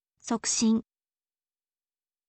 sokushin